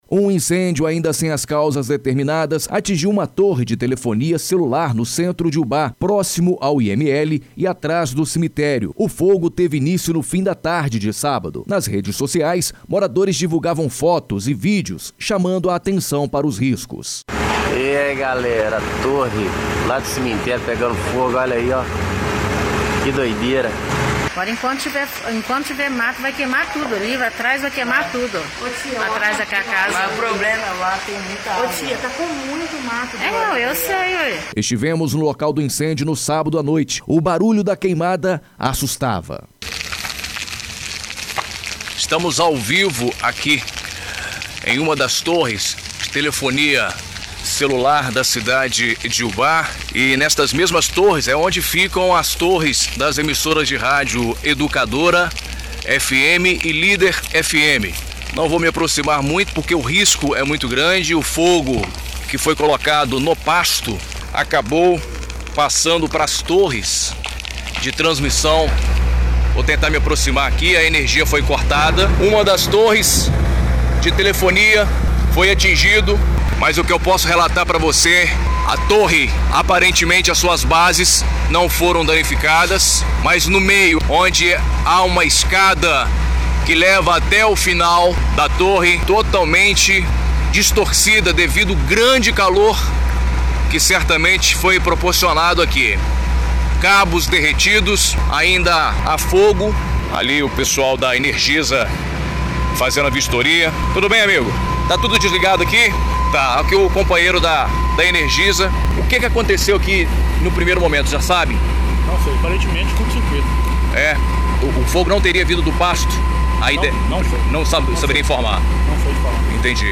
Ouça matéria exibida no Jornal em dia com a Notícia na Rádio Educadora nesta segunda – feira(15/07)